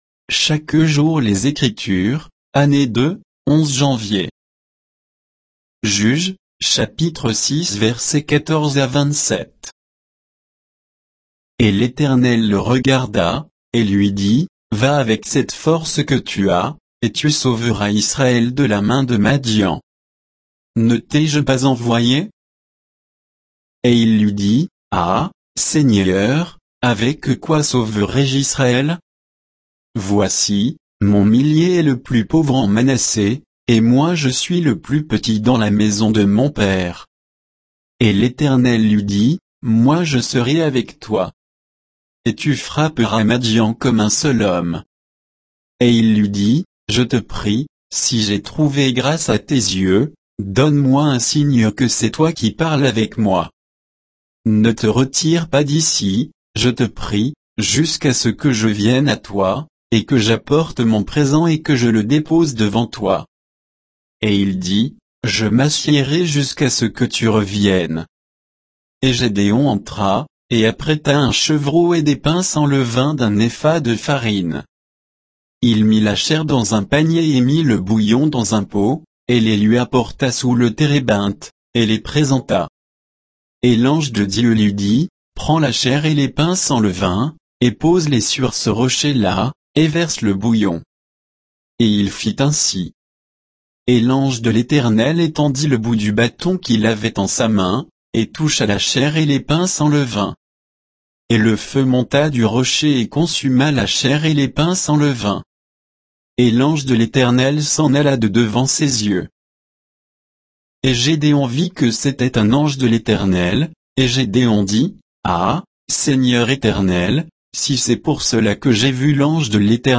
Méditation quoditienne de Chaque jour les Écritures sur Juges 6